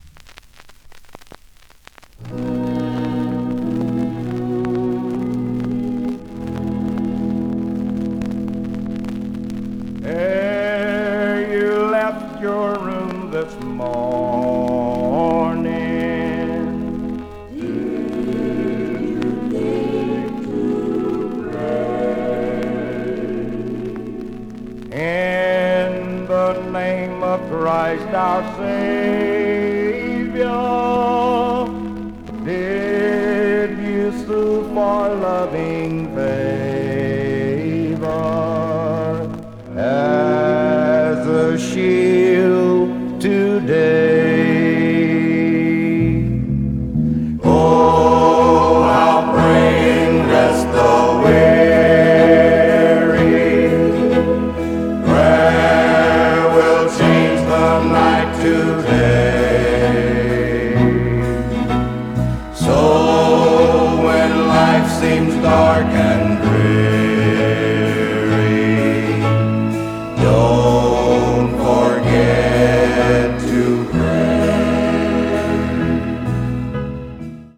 Check out the clips below, which contain “before and after” examples of the unprocessed audio followed by the results of our mixing, mastering or restoration services.
Worn-out 45.
As you’ll hear below, we take the time to restore most damaged vinyl to near perfection!